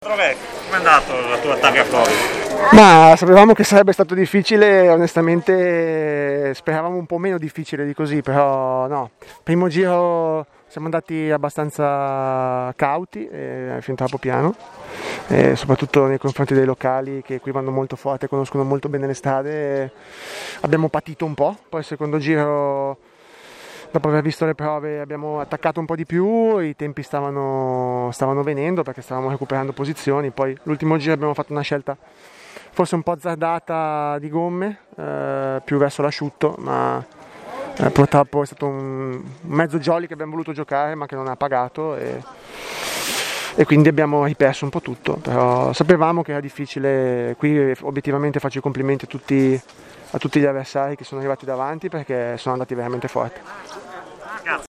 Interviste Rally Targa Florio 2020
Interviste di fine rally